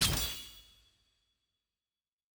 sfx-exalted-shop-purchase-button-click.ogg